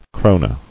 [kro·ne]